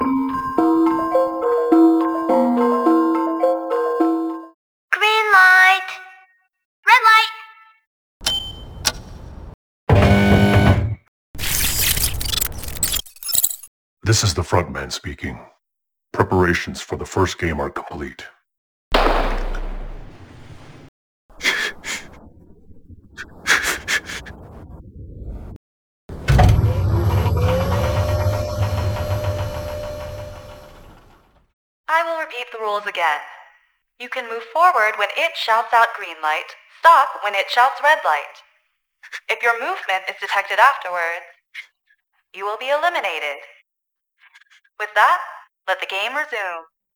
• 42 Dialogue Samples, 9 Music Samples, 77 Sound Effects